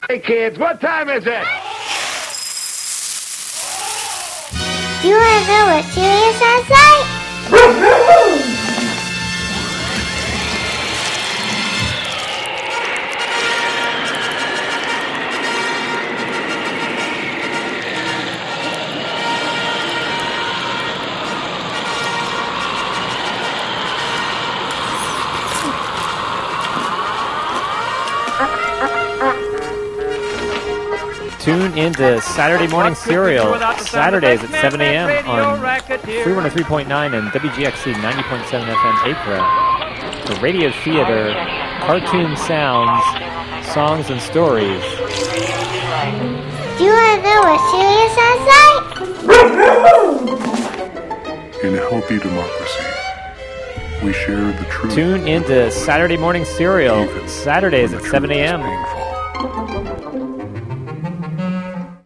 An Official PSA for "Saturday Morning Serial" show, Saturdays at 7 a.m. on WGXC 90.7-FM.
"Saturday Morning Serial" features radio theatre, cartoon music, sound effects, funny sounds, historic audio, and sometimes themed shows.